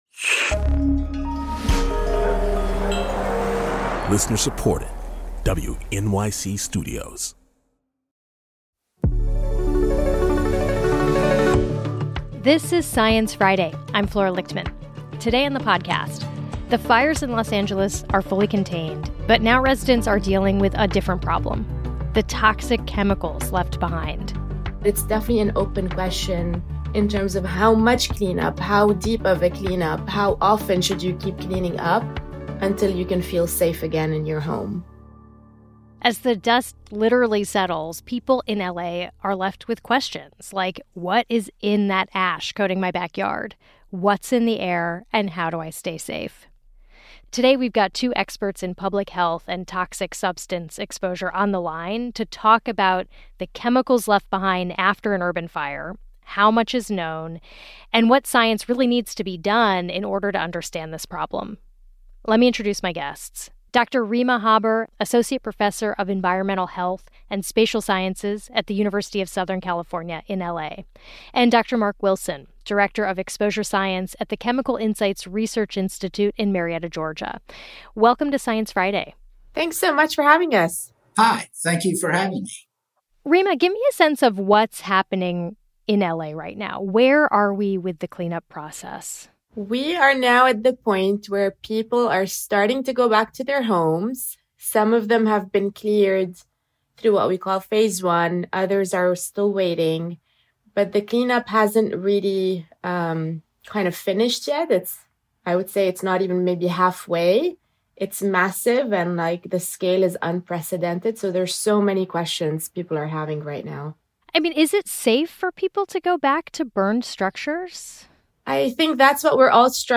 talks with two experts in public health and toxic substance exposure